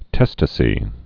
(tĕstə-sē)